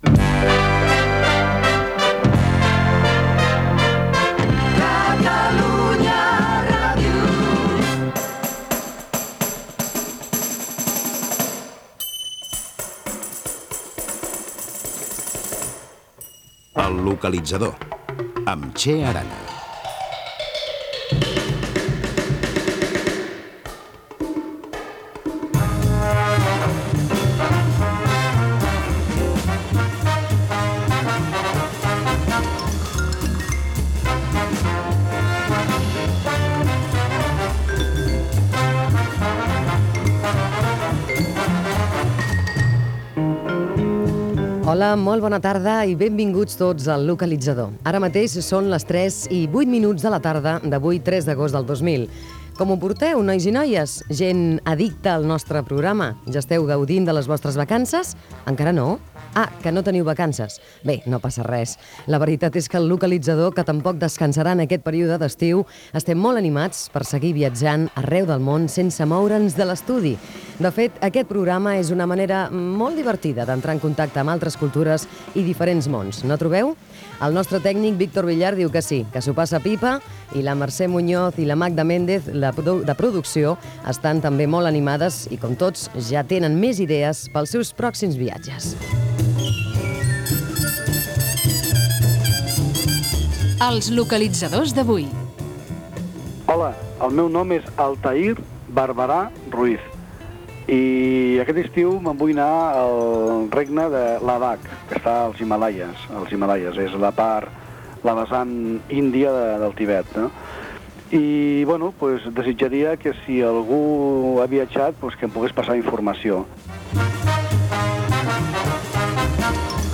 Indicatiu de Catalunya Ràdio
Presentació del programa, data, menció de l'equip. Tall d'oient demanant informació per fer un viatge a Himàlaia
Música del Tibet i dades d'Himàlaia.